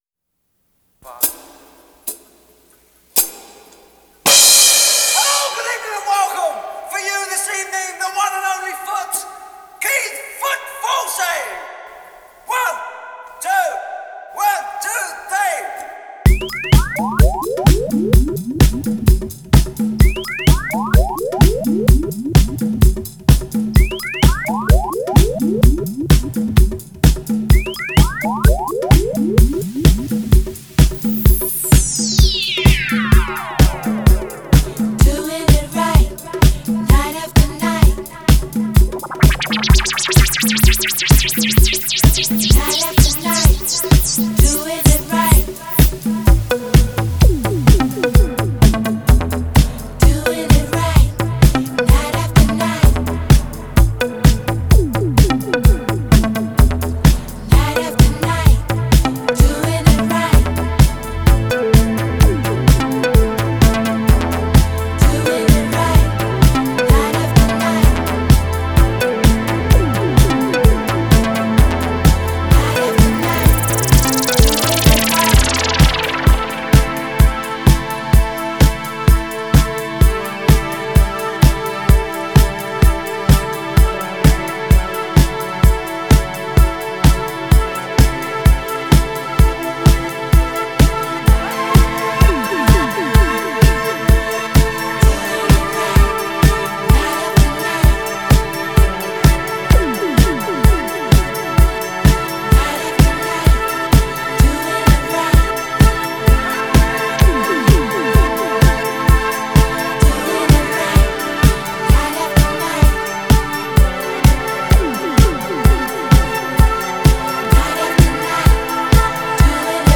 Genre: Dance, Disco, Nu-Disco, Funk